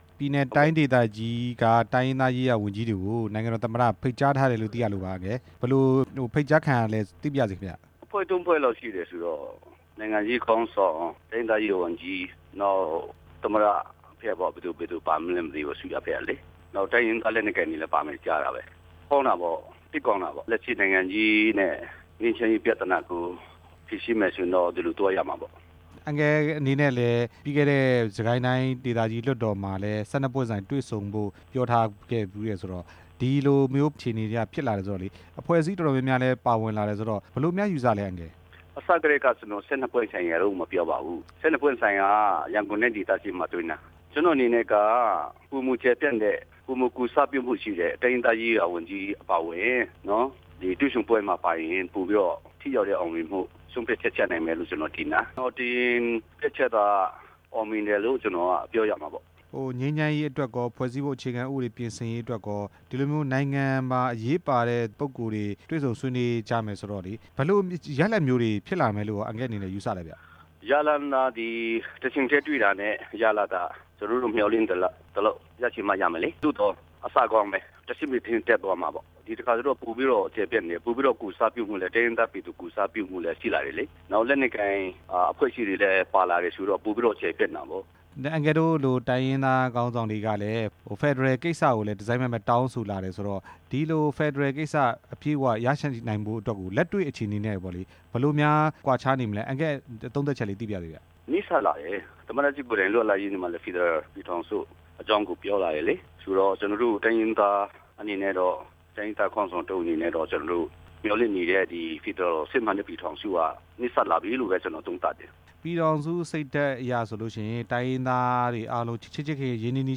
သမ္မတရုံးက ဖိတ်ကြားခံထားတဲ့ ချင်းတိုင်းရင်းသားရေးရာဝန်ကြီး ဦးနိုထန်ကပ်နဲ့ မေးမြန်းချက်